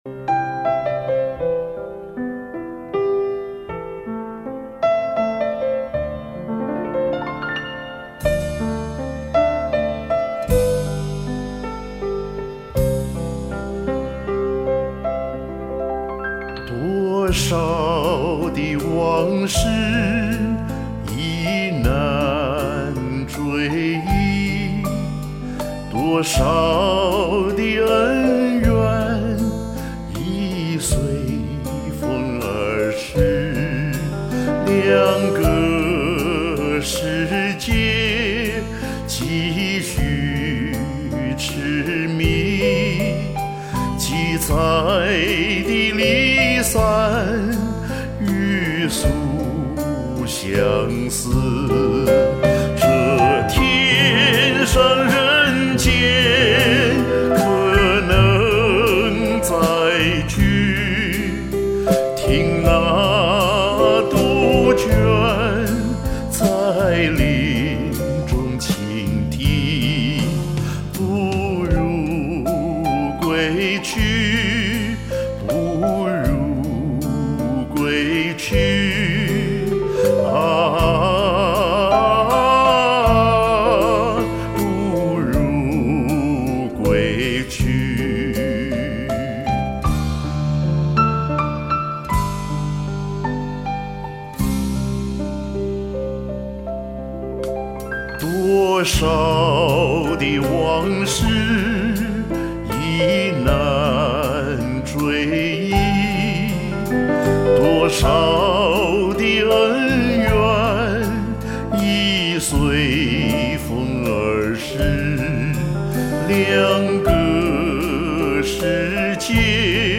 2前年还录过一个低音版